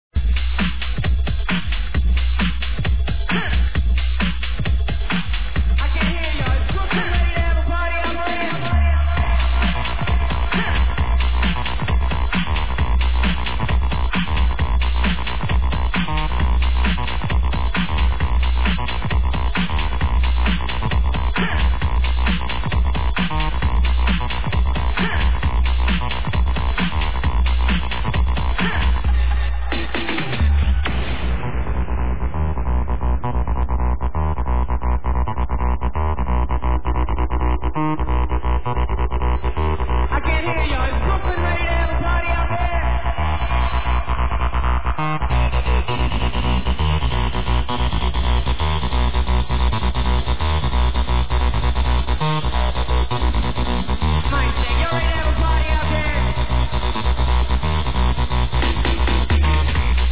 This is an acidy 303 type track